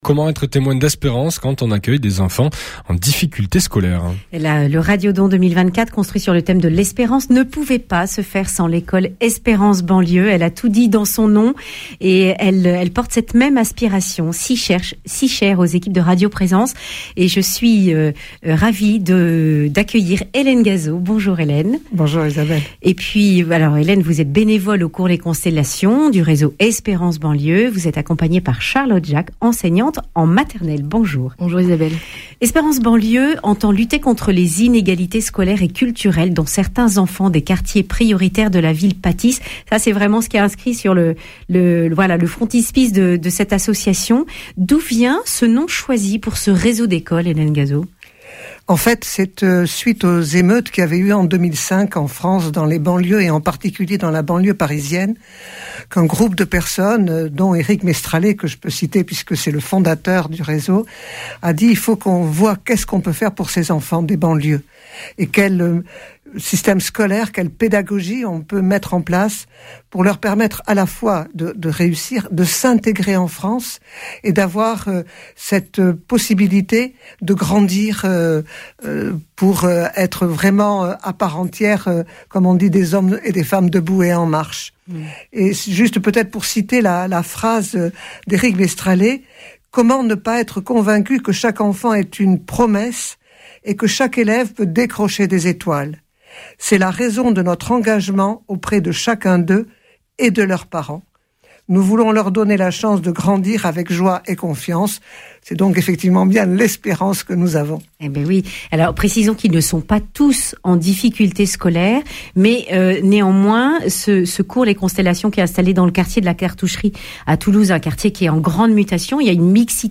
vendredi 22 novembre 2024 Le grand entretien Durée 10 min